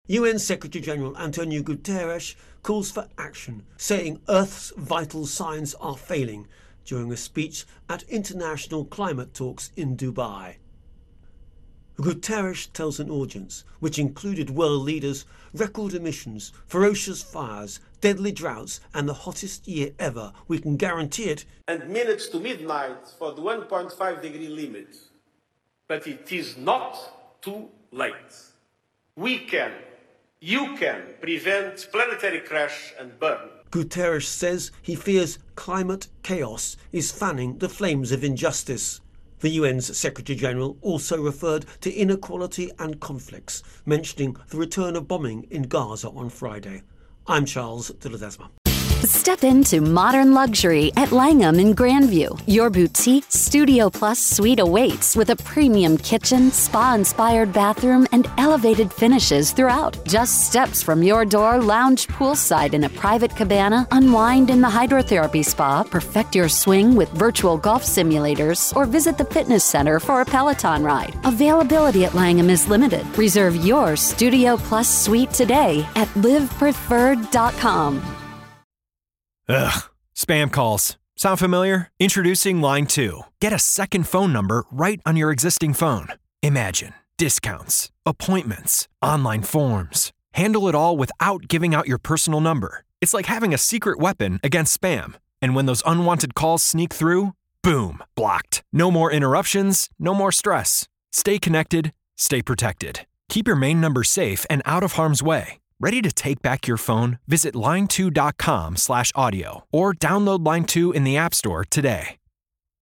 reports on COP28 Climate Summit.